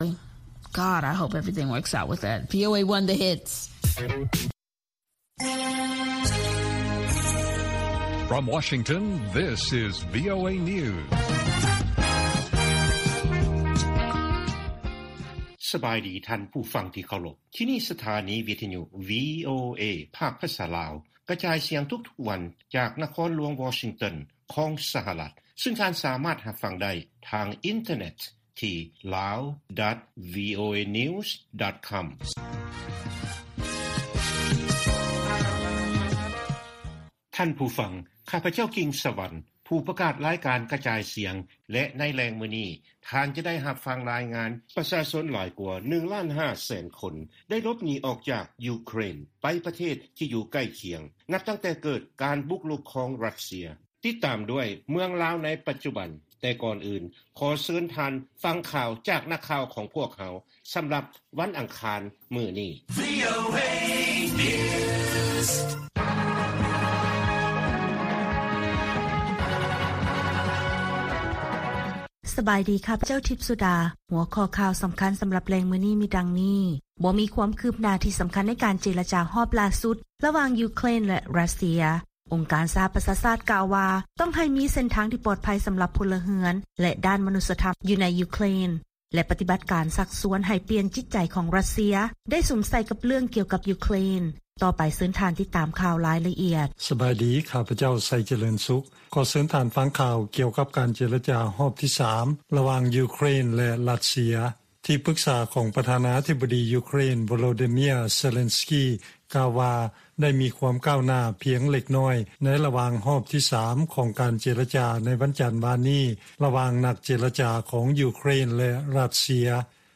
ລາຍການກະຈາຍສຽງຂອງວີໂອເອ ລາວ: ບໍ່ມີຄວາມຄືບໜ້າທີ່ສຳຄັນ ໃນການເຈລະຈາ ຮອບຫຼ້າສຸດ ລະຫວ່າງ ຢູເຄຣນ ແລະ ຣັດເຊຍ